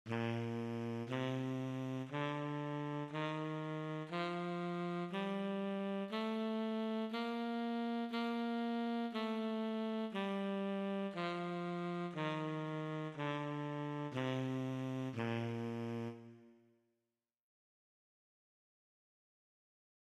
Il sassofono tenore ha un timbro più vellutato e più carnoso nei suoni gravi come in quelli acuti ed è un po' meno virtuoso del sassofono contralto, ma altrettanto melodioso.
Saxofono Tenore
Saxtenore.mp3